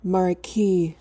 marquee.mp3